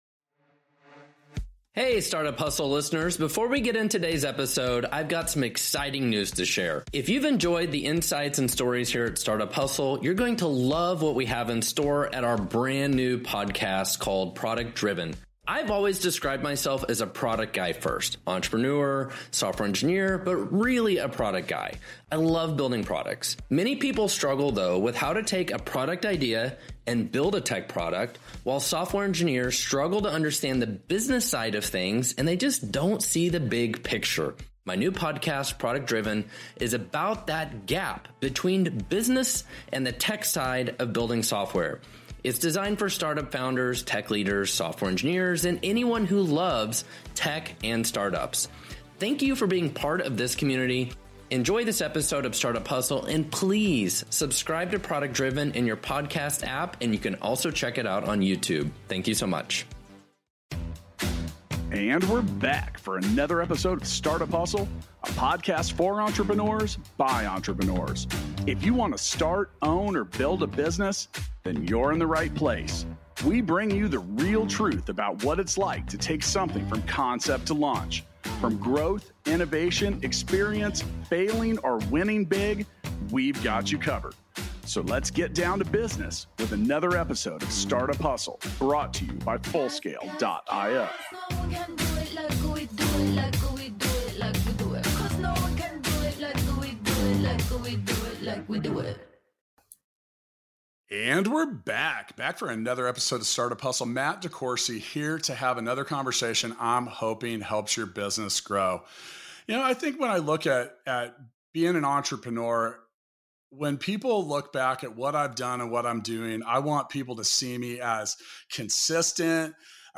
Listen to their conversation as Frank shares his baseball journey from being undrafted to becoming an All-Star. They also talk about finding work-life balance, staying focused, dealing with setbacks, and how to achieve your goals.